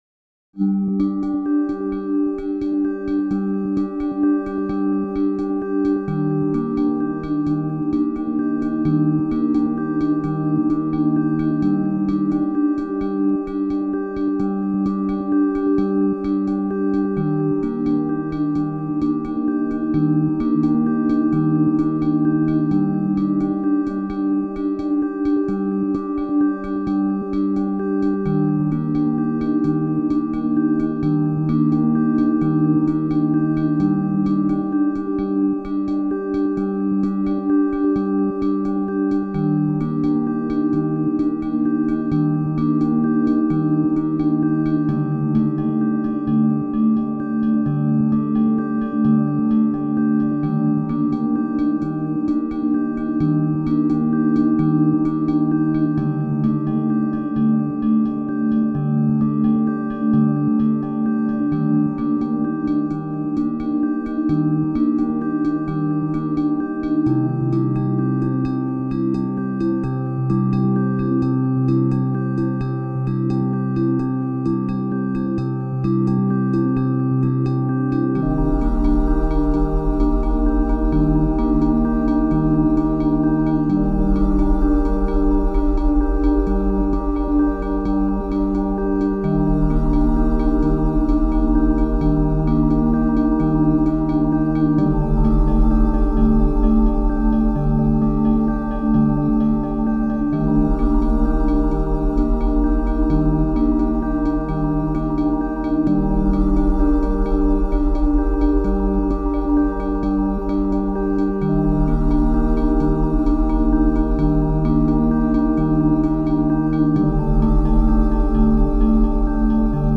Soundtrack, Ambient